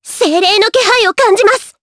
Dosarta-Vox_Skill2_jp.wav